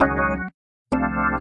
键盘 " 风琴03
描述：记录自DB33Protools Organ。 44khz 16位立体声，无波块。
Tag: 键盘 器官 DB33